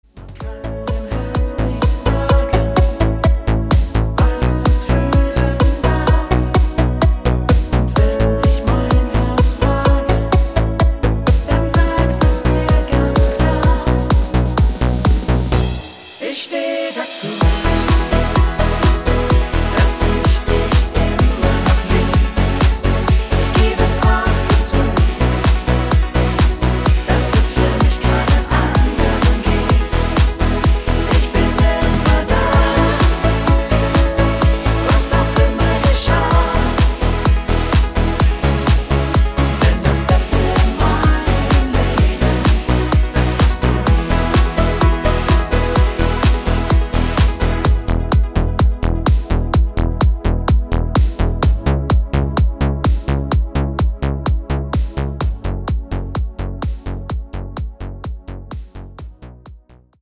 Männer Version